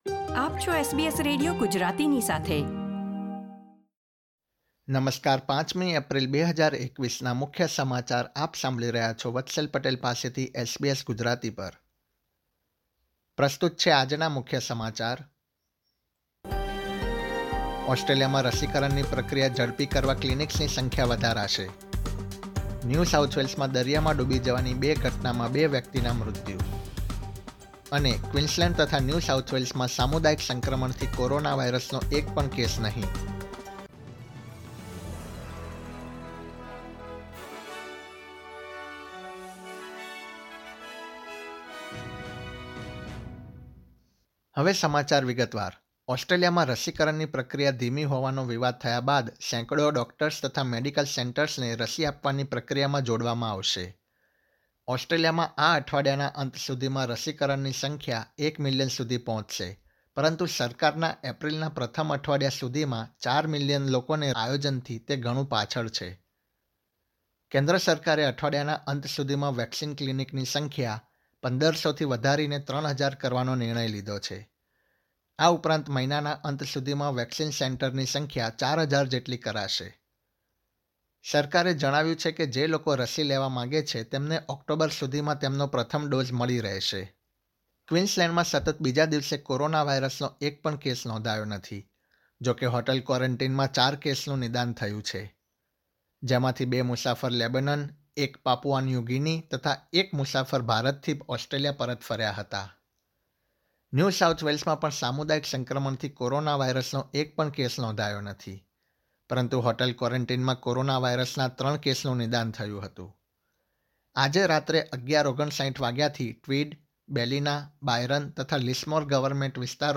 gujarati_0504_newsbulletin.mp3